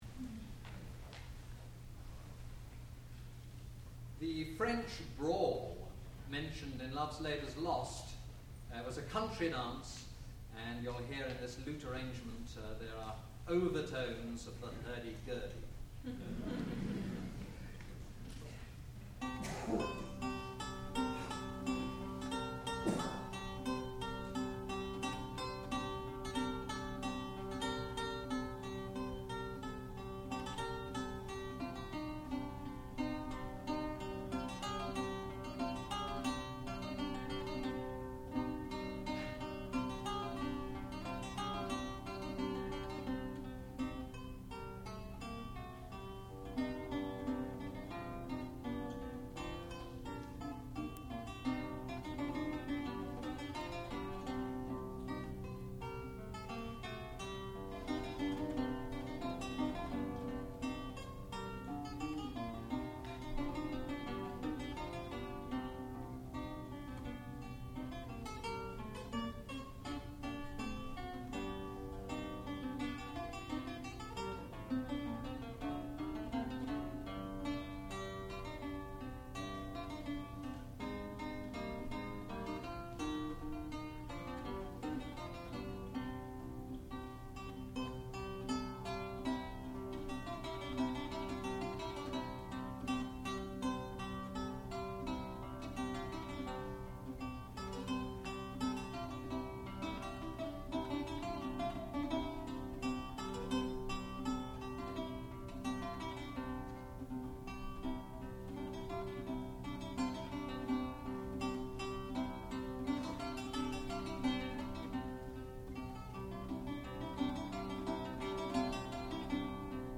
A French brawl (lute solo)
sound recording-musical
classical music